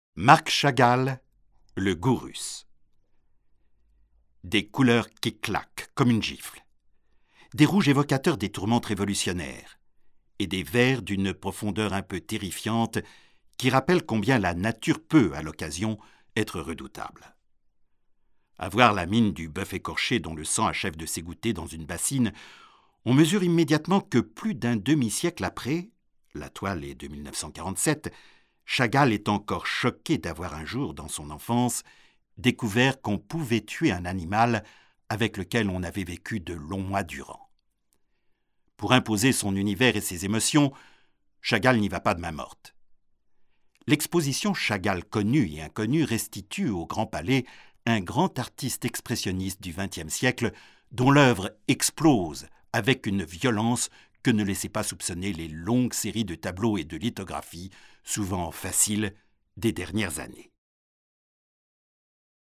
Kein Dialekt
Sprechprobe: eLearning (Muttersprache):
french voice over artist.